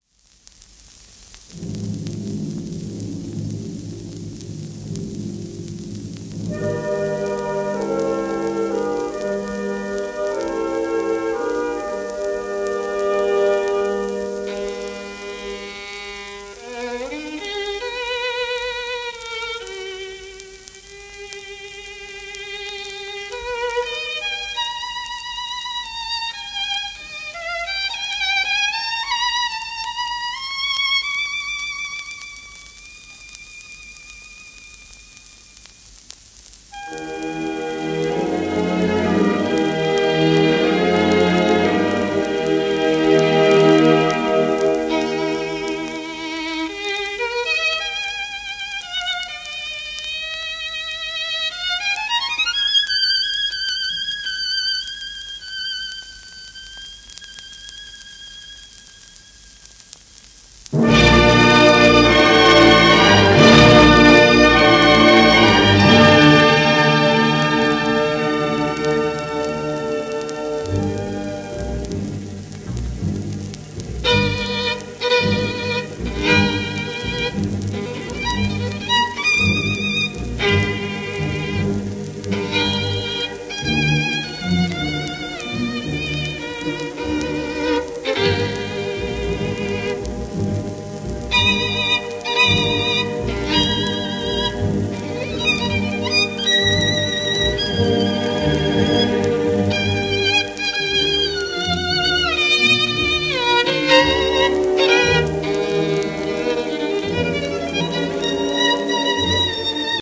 盤質A- *軽微な小キズ